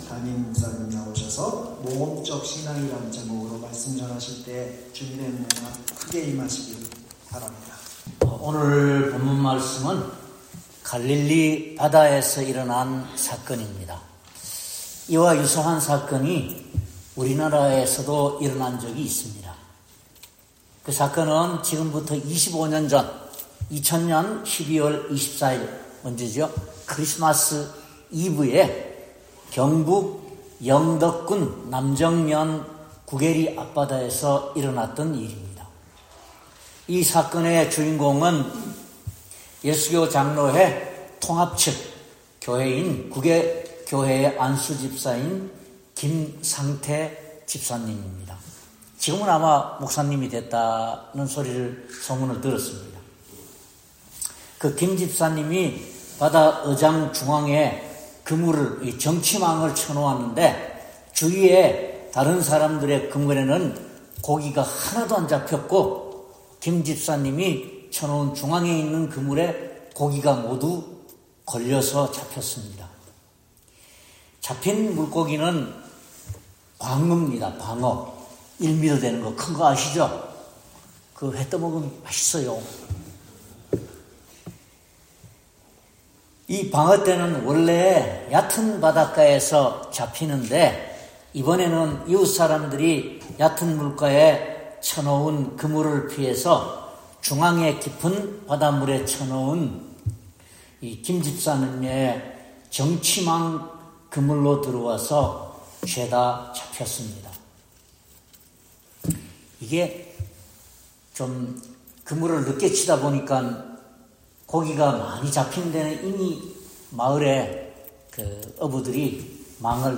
Bible Text: 눅 5:1-11 | 설교자